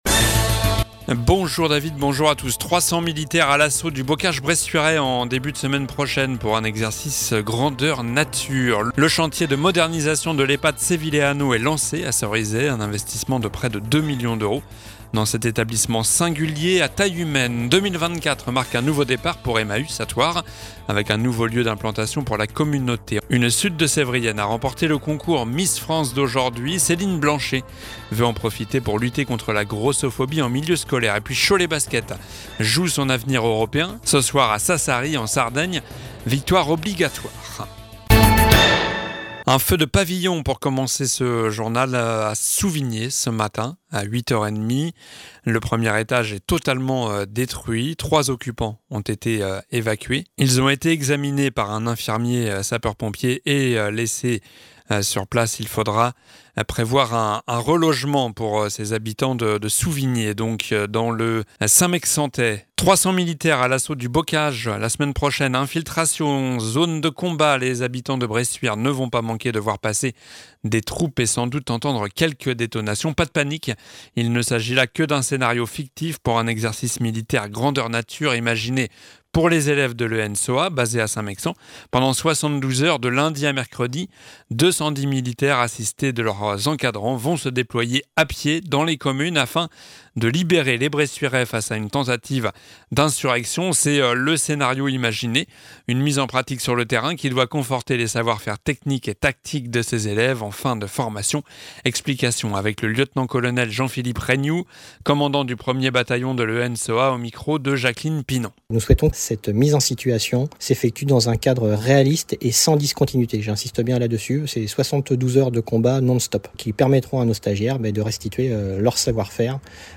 Journal du mercredi 10 janvier (midi)